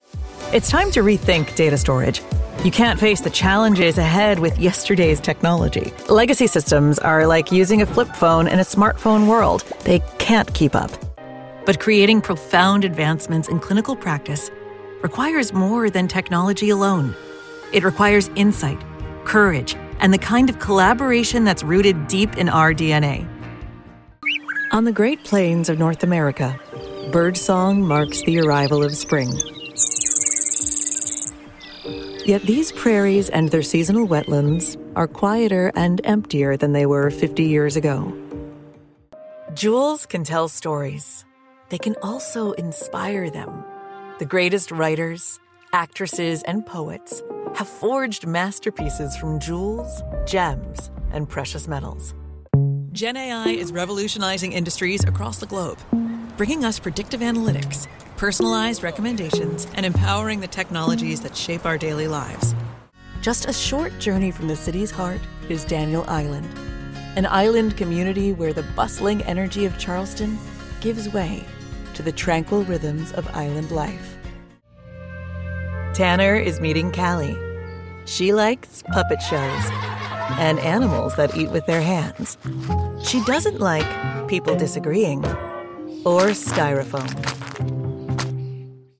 Adult
Has Own Studio
standard us | natural
NARRATION 😎
gravitas
smooth/sophisticated
warm/friendly
husky